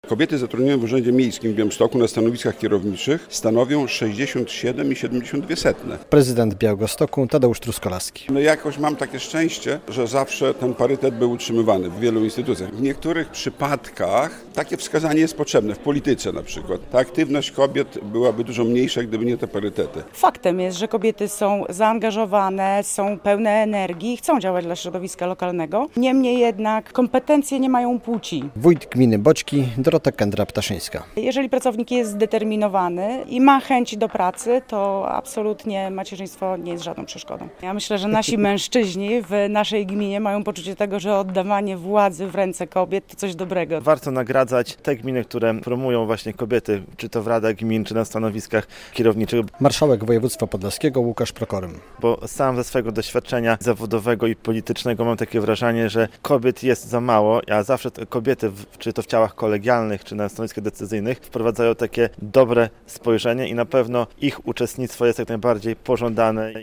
Podlaskie samorządy nagrodzone za wspieranie kobiet - relacja